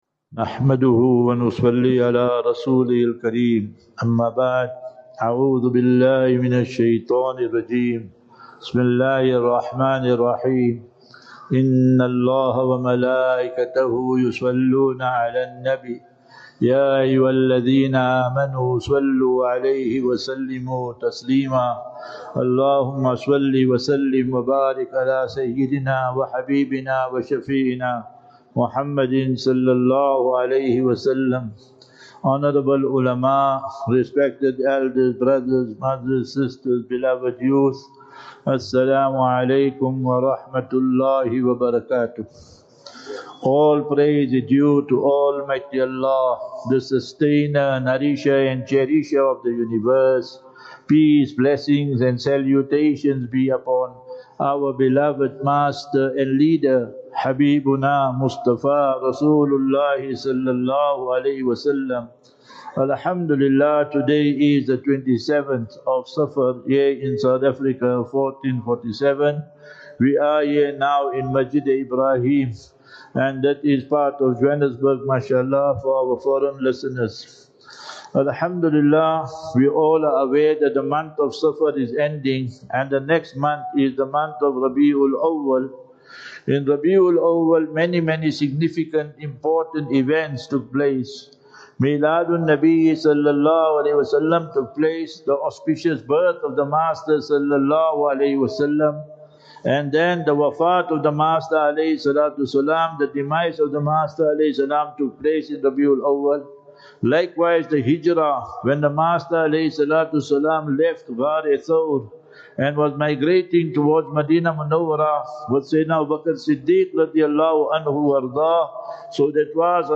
22 Aug 22 August 2025 - Jumu'ah Lecture at Masjid Ebrahim (Southdale) JHB.